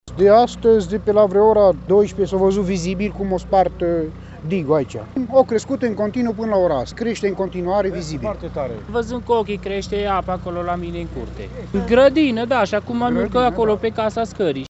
Locuitorii din zonă au văzut apa crescând de la o clipă la alta:
stiri-22-mai-voxuri-inundatii.mp3